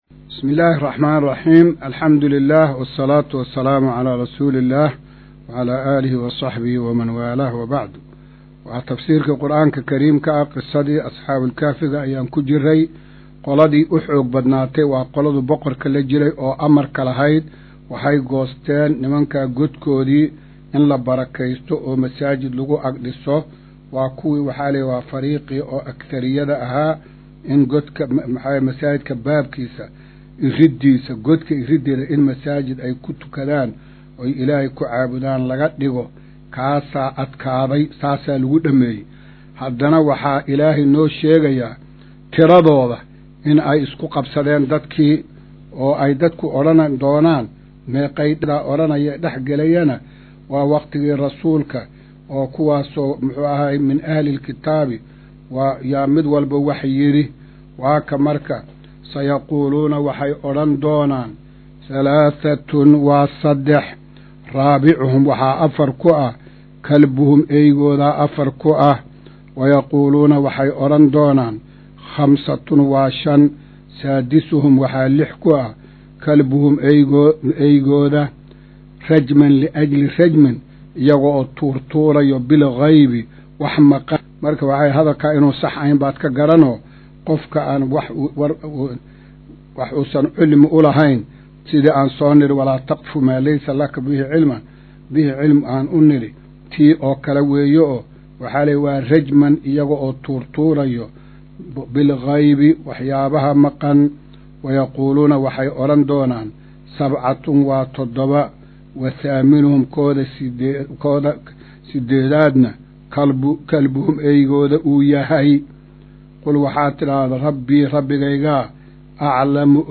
Maqal:- Casharka Tafsiirka Qur’aanka Idaacadda Himilo “Darsiga 144aad”